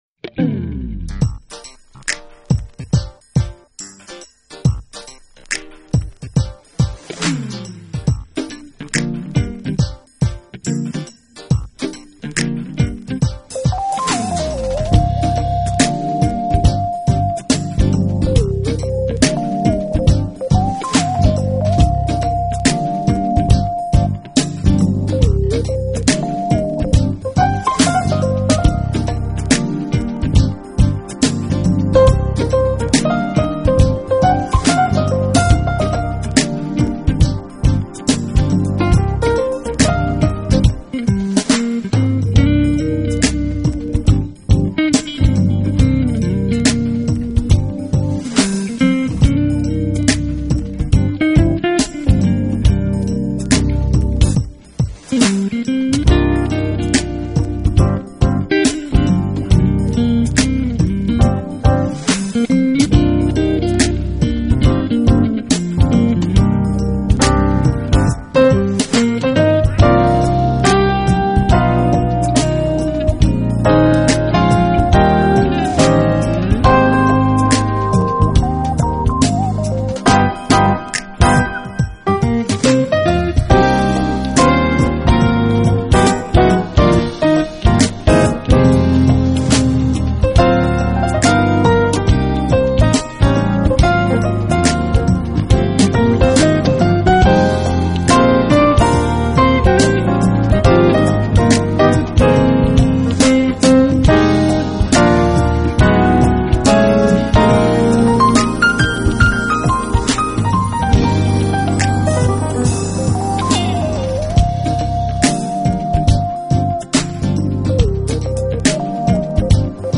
【爵士键盘】